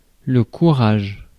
Ääntäminen
Ääntäminen accents without the "Hurry-furry" merger accents with the "Hurry-furry" merger: IPA : [ˈkɝ.ɹɪdʒ] UK : IPA : /ˈkʌɹ.ɪdʒ/ US : IPA : /ˈkʌɹ.ɪdʒ/ Tuntematon aksentti: IPA : /ˈkɝɪdʒ/ IPA : /ˈkʌrɪdʒ/